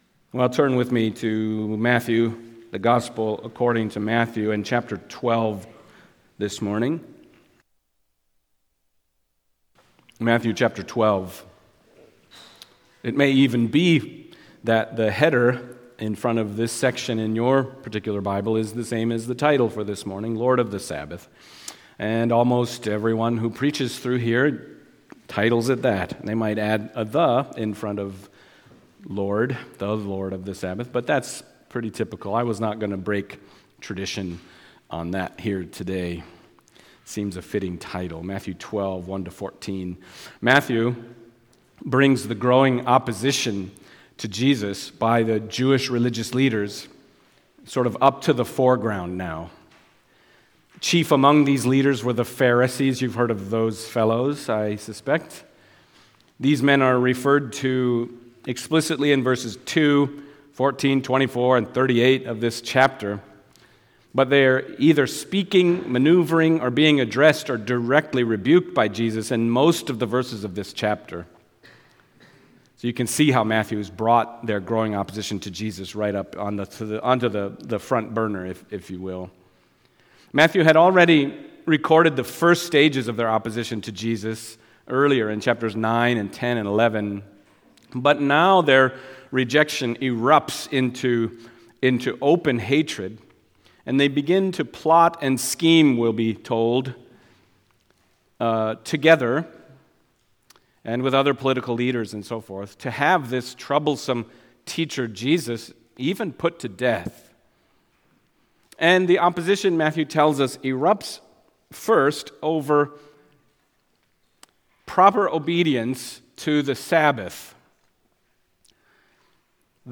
Matthew Passage: Matthew 12:1-14 Service Type: Sunday Morning Matthew 12:1-14 « Profaning the Covenant